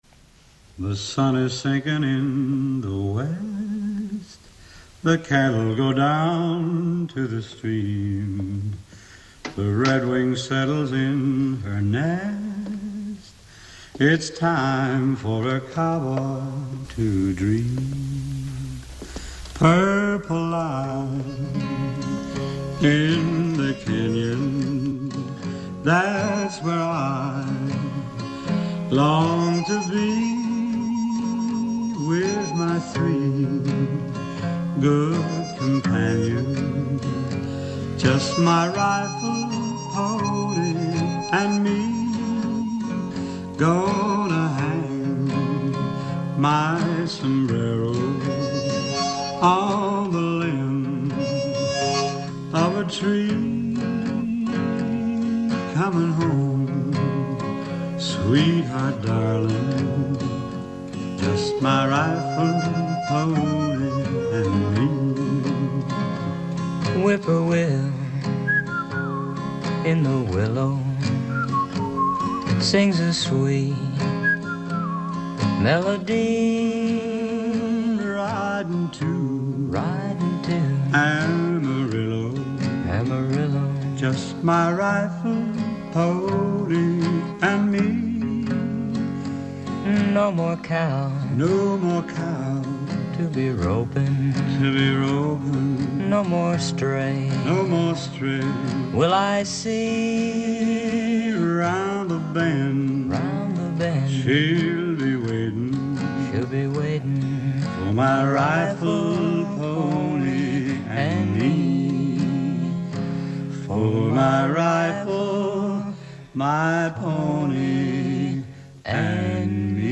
Country, Western